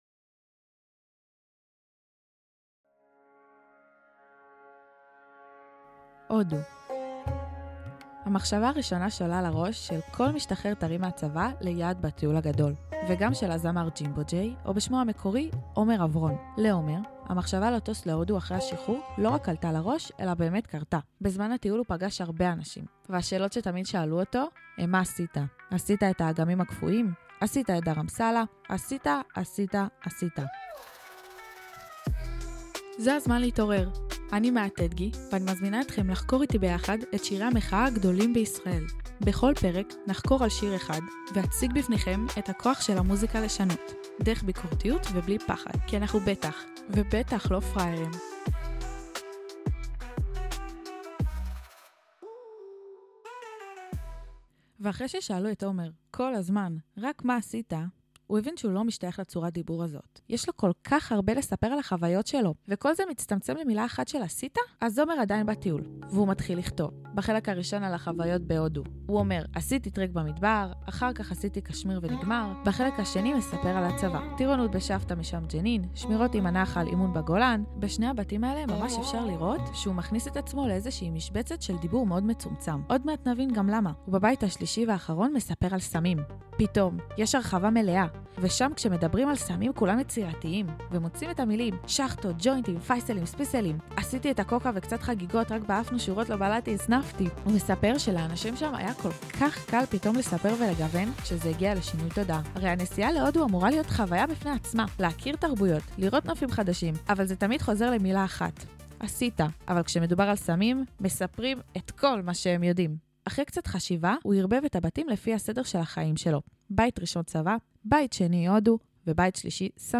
שיר שנכתב בזמן הטיול אחרי צבא להודו והפך להיות להיט ענק בישראל. בריאיון עם פסיכולוגית חינוכית ננסה להבין מהי קונפורמיות ואיך היא קשורה לשיר של ג'ימבו ג'יי.